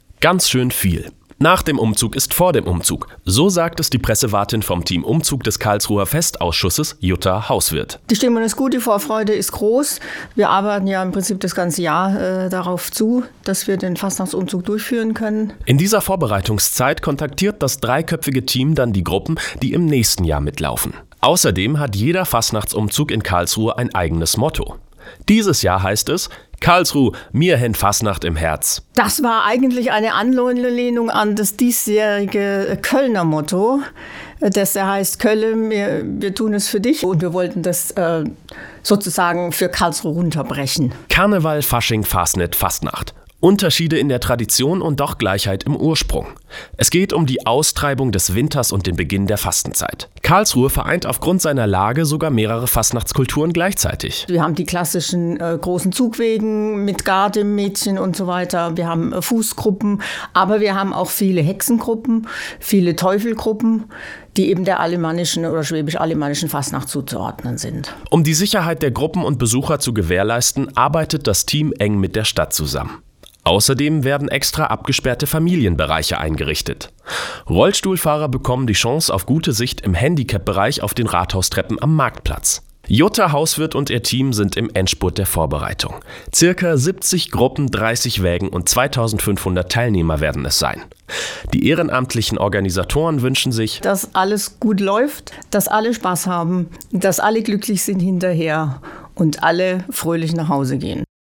Ein Radiobeitrag